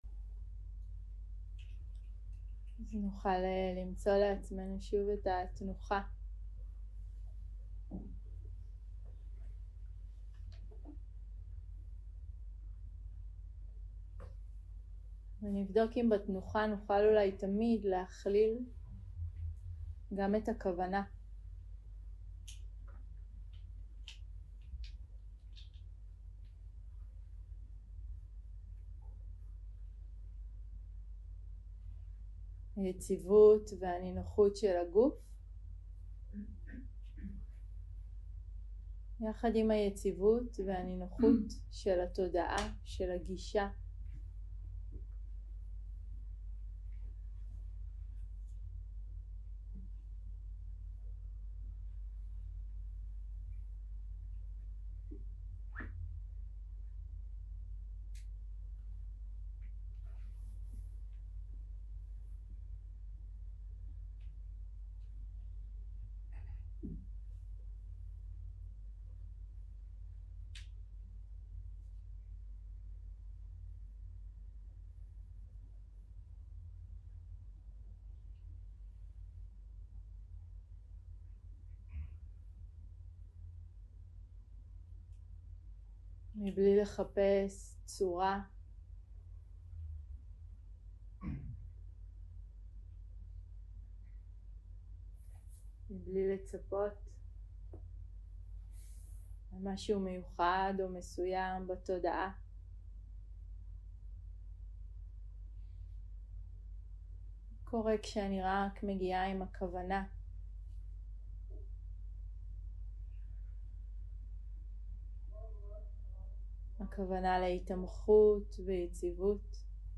סוג ההקלטה: מדיטציה מונחית